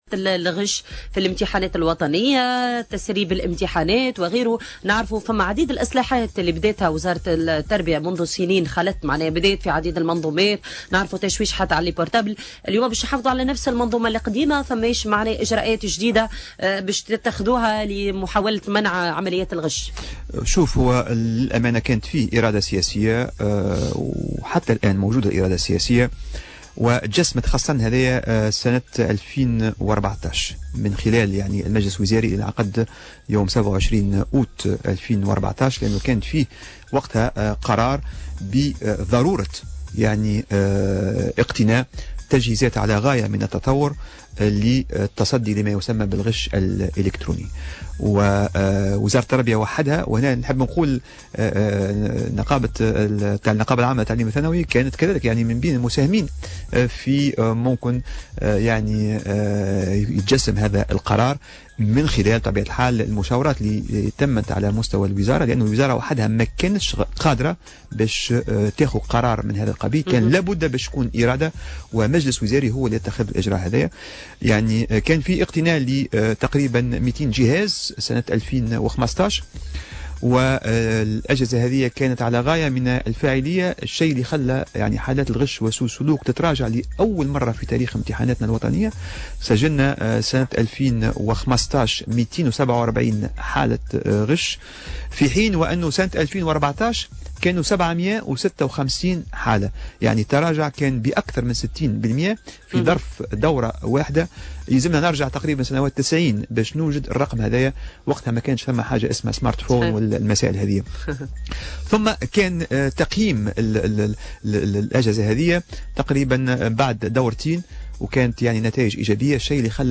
Intervenu sur Jawhara FM